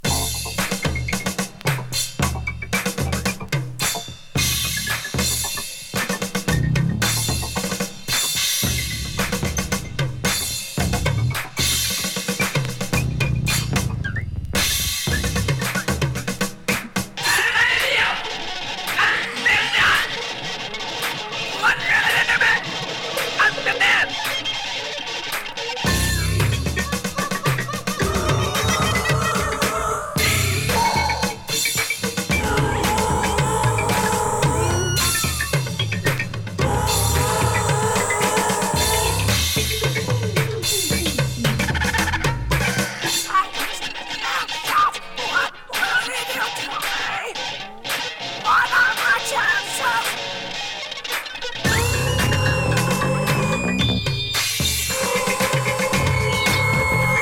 Avan Cold Funk